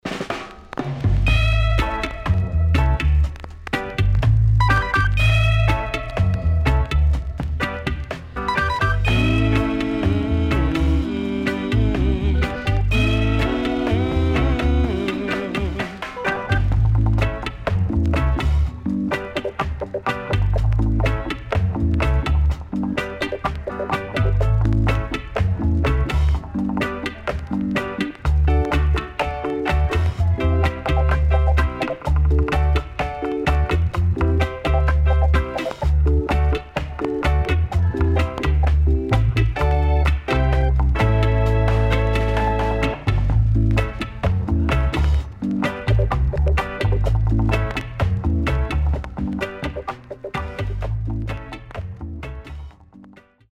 HOME > REGGAE / ROOTS  >  RECOMMEND 70's
Good Roots Vocal
SIDE A:所々ジリジリしたノイズがあり、少しプチノイズ入ります。